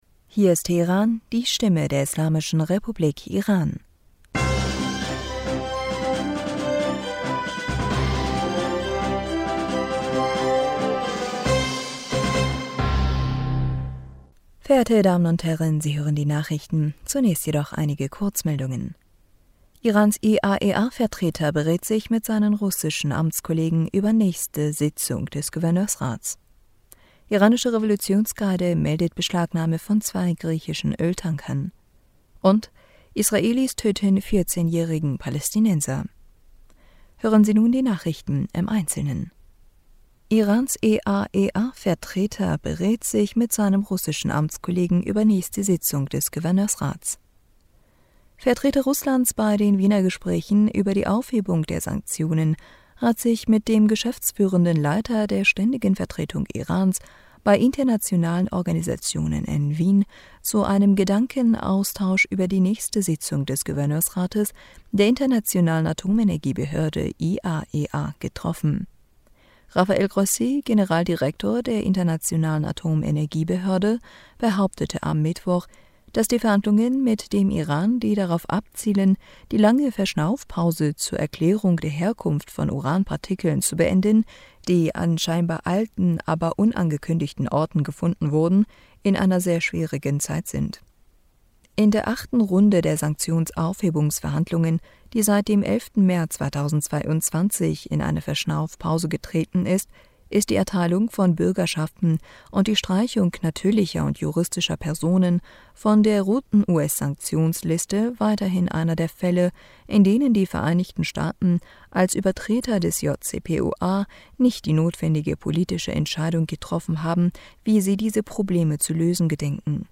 Nachrichten vom 28. Mai 2022
Die Nachrichten von Samstag dem 28. Mai 2022